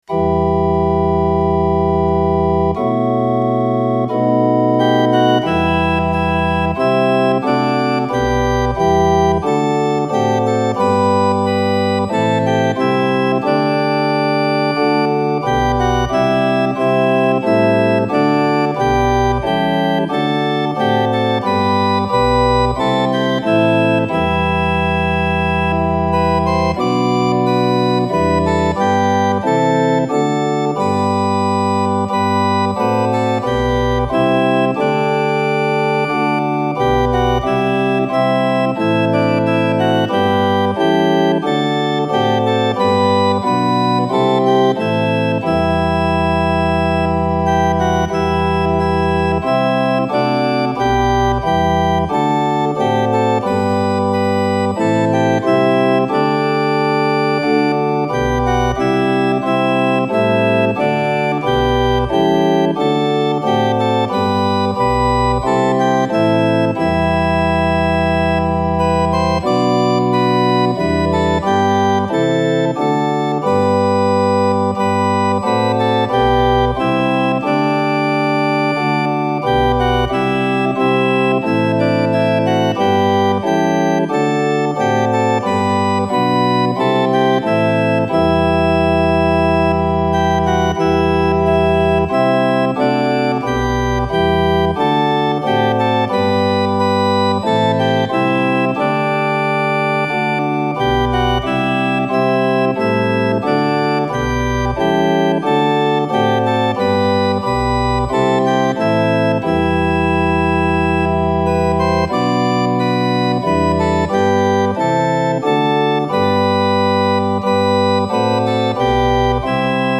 fine traditional tune